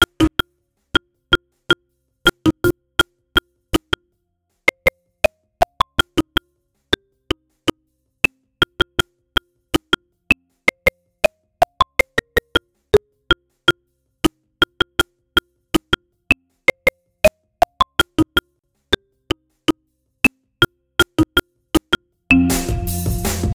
happy_marimba_cut.wav